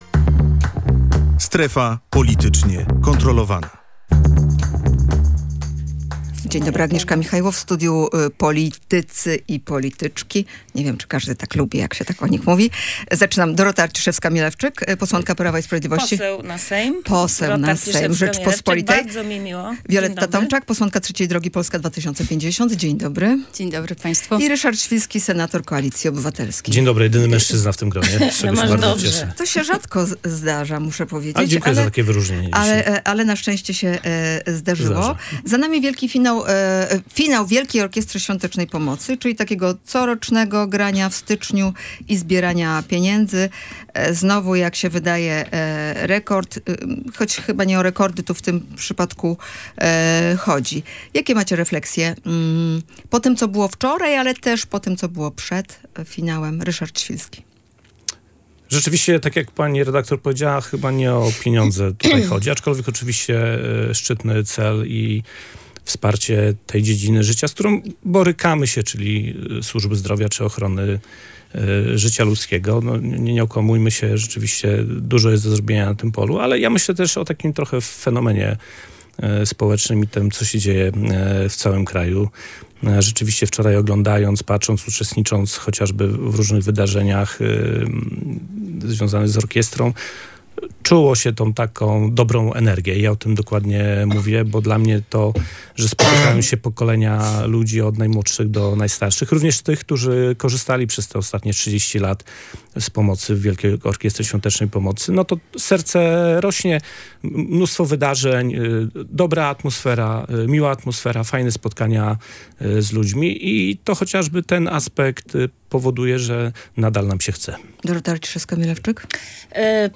Problem omawiali: Dorota Arciszewska-Mielewczyk, posłanka Prawa i Sprawiedliwości, Wioleta Tomczak, posłanka Polski 2050, oraz Ryszard Świlski, senator Koalicji Obywatelskiej.